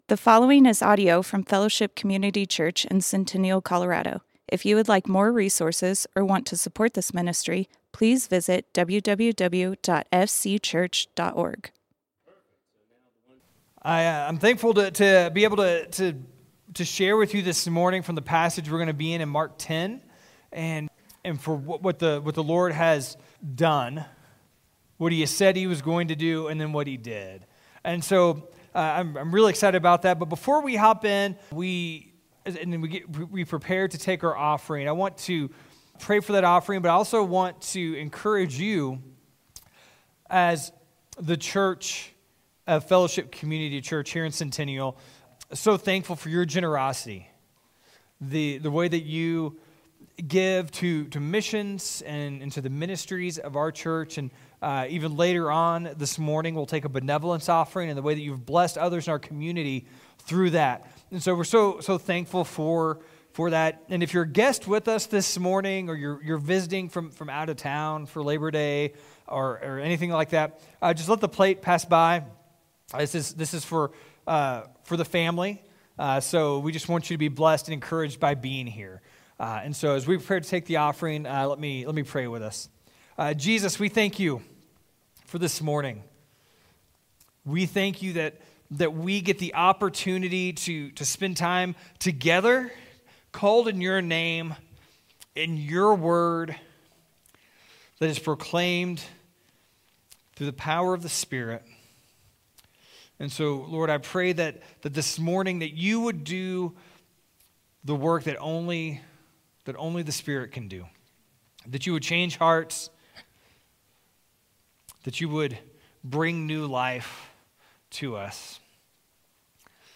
Fellowship Community Church - Sermons Not So Among You Play Episode Pause Episode Mute/Unmute Episode Rewind 10 Seconds 1x Fast Forward 30 seconds 00:00 / 32:18 Subscribe Share RSS Feed Share Link Embed